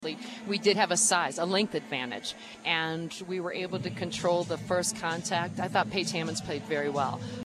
Head coach Mary Wise credited the team’s athleticism to their success around the net: